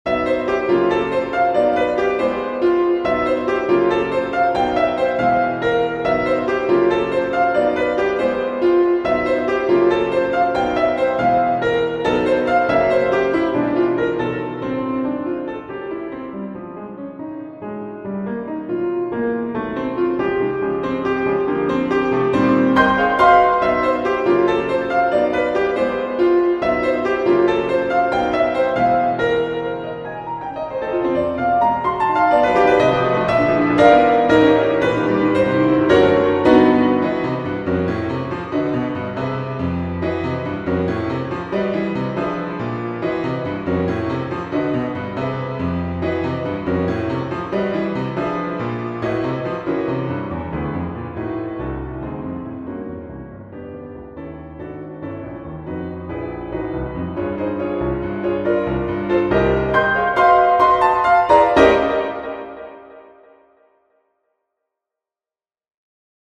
Persichetti Exercise 7 - 49 for Piano